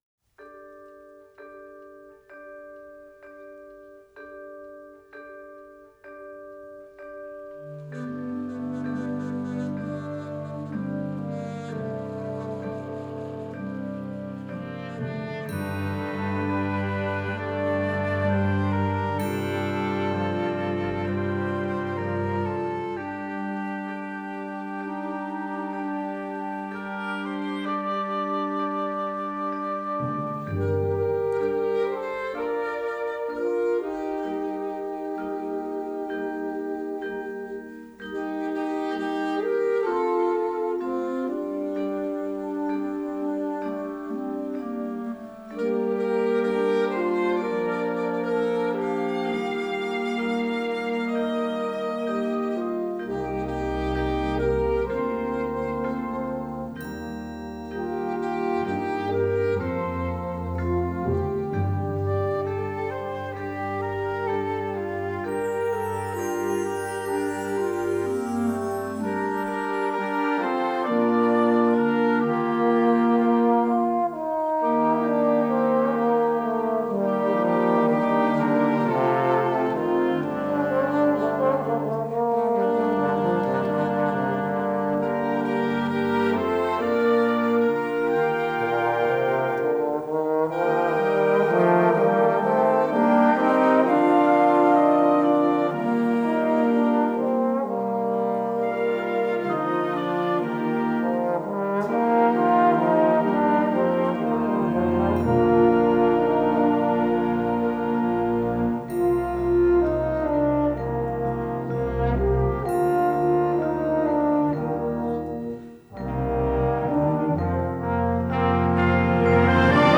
Horn and Band